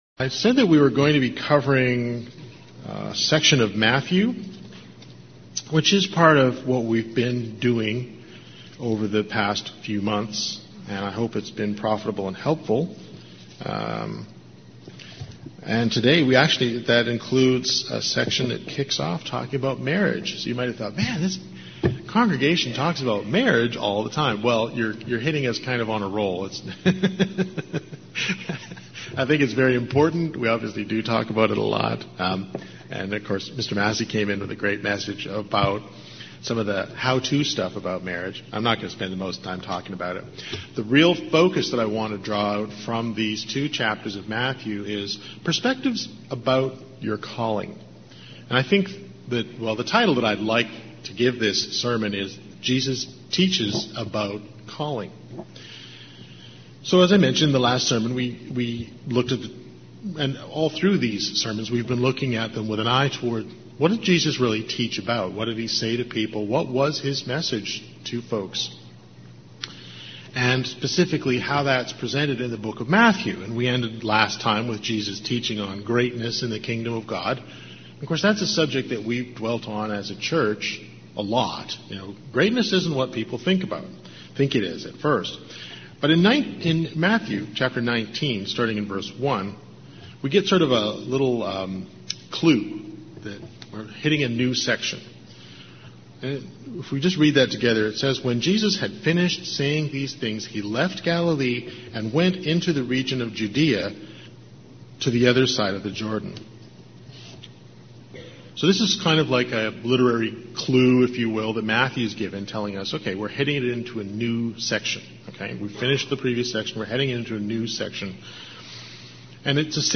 The last sermon we had in this series looked at the teachings of Jesus as presented in the book of Matthew.